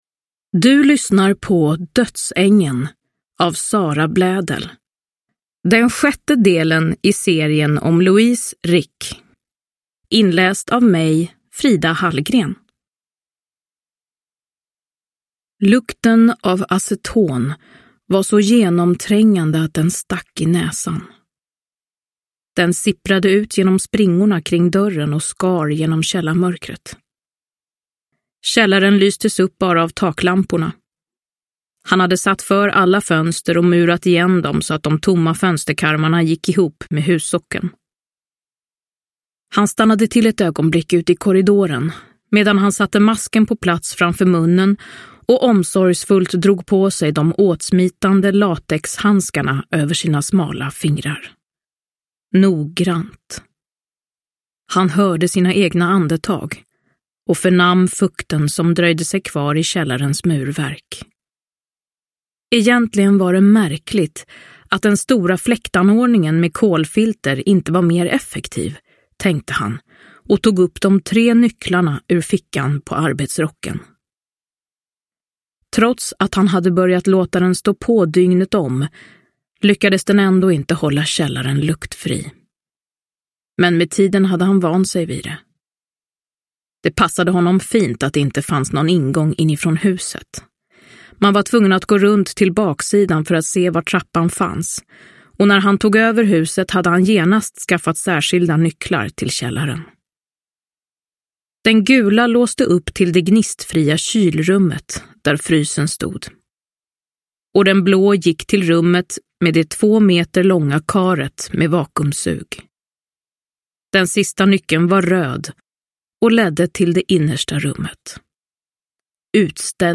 Dödsängeln – Ljudbok – Laddas ner
Uppläsare: Frida Hallgren